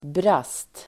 Uttal: [bras:t]